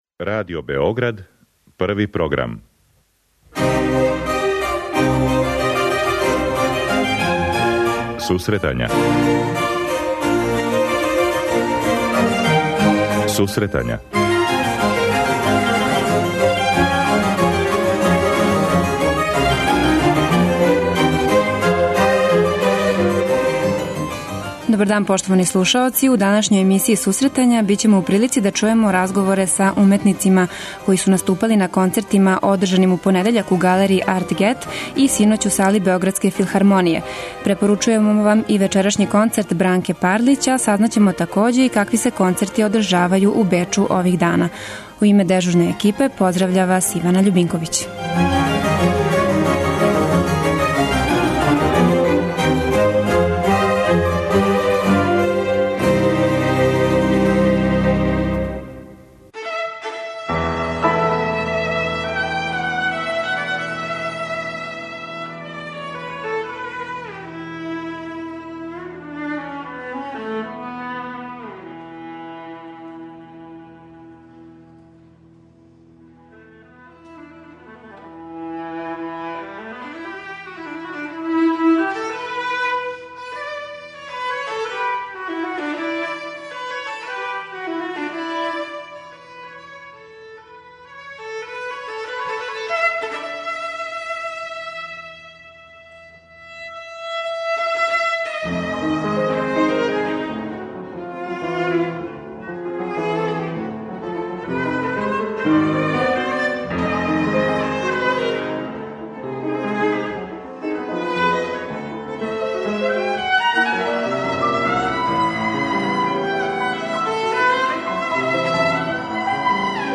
Чућемо разговоре са уметницима који су наступали, а током емисије биће речи и о Ускршњим концертима у Бечу.